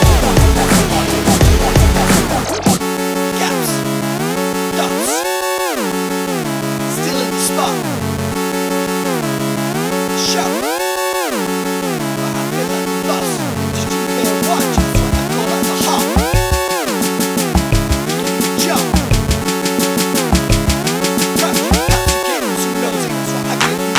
no Backing Vocals R'n'B / Hip Hop 3:30 Buy £1.50